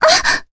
Cassandra-Vox_Damage_jp_01.wav